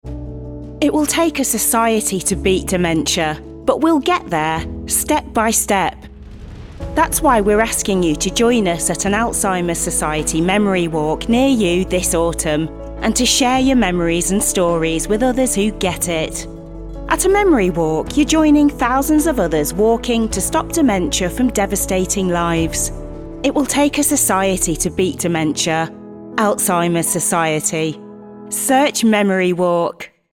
Clair comme du cristal, chaleureux et polyvalent. Une voix britannique naturellement douce – un son neutre avec une douce touche des Midlands ou du Nord si nécessaire.
Home studio : Cabine vocale spécialement conçue, interface Audient ID14, Neumann U87ai et Neumann TLM 102. Source Connect Standard.